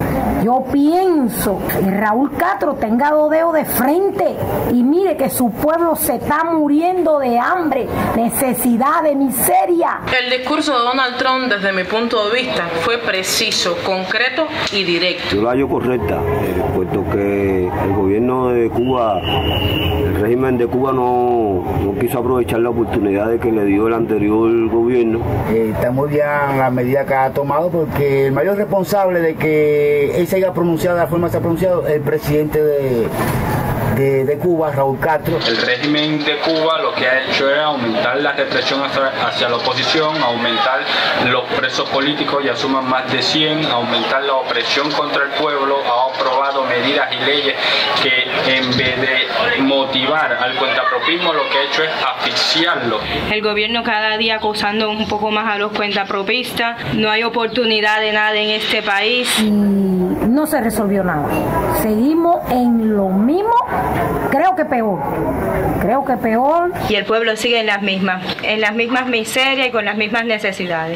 Un sondeo de opinión realizado por la opositora Unión Patriótica de Cuba en la región oriental de la isla sobre el anuncio de la nueva política del presidente de los Estados Unidos, Donald Trump, señala al gobernante Raúl Castro como el principal causante de la crisis económica y social que atraviesa el país.